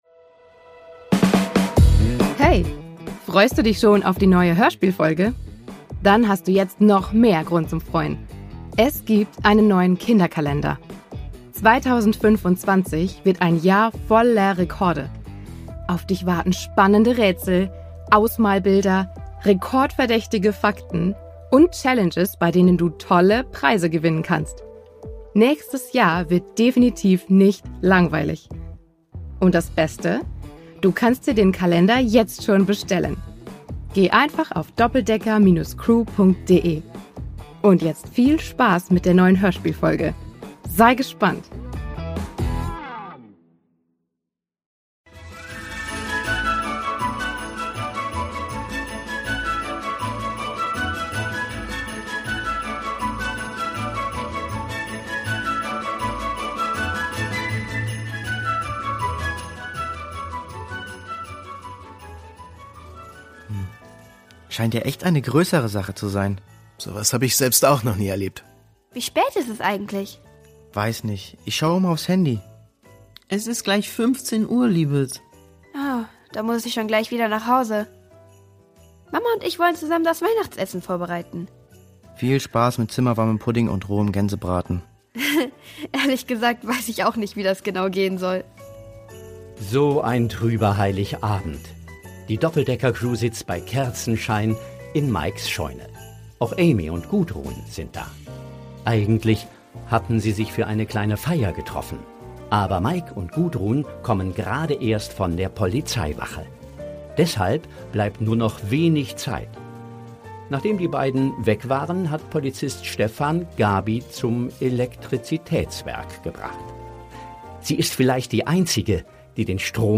Weihnachten 5: Computergenie am Werk | Die Doppeldecker Crew | Hörspiel für Kinder (Hörbuch) ~ Die Doppeldecker Crew | Hörspiel für Kinder (Hörbuch) Podcast